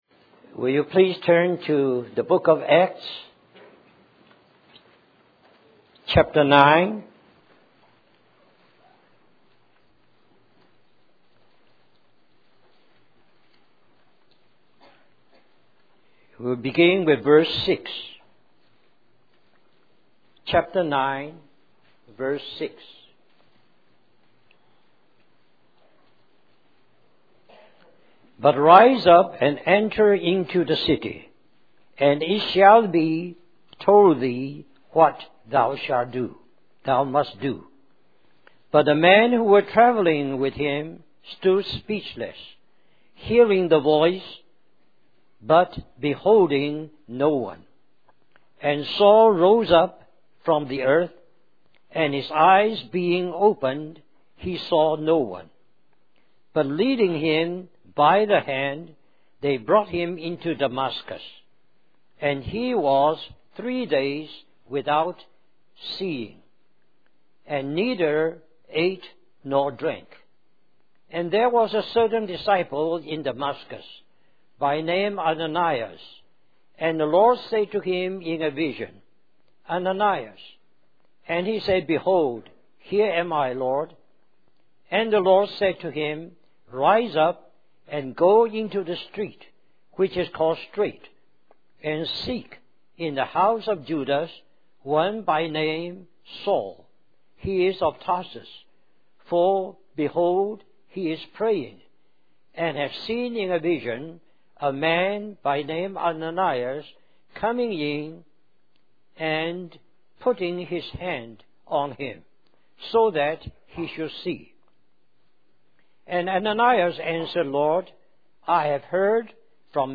In this sermon, the speaker emphasizes the importance of the heavenly vision that the Apostle Paul saw on the road to Damascus.